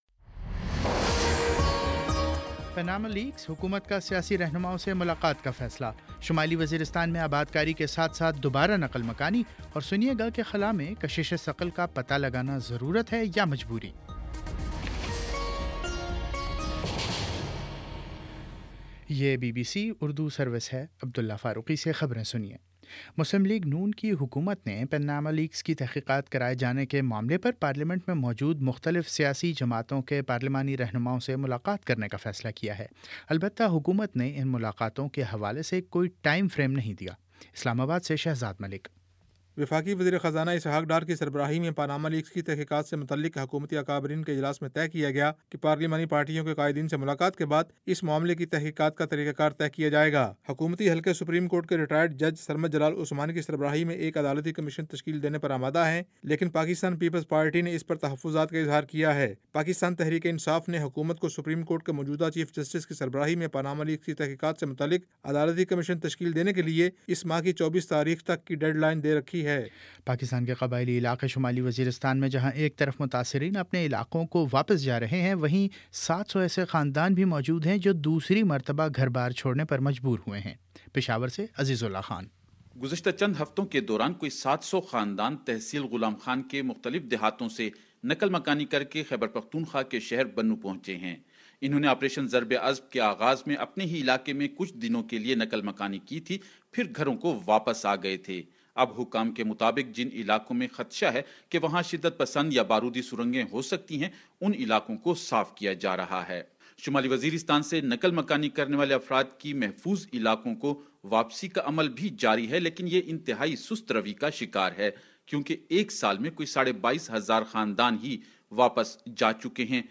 اپریل 18 : شام پانچ بجے کا نیوز بُلیٹن